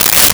Tear Paper 02
Tear Paper 02.wav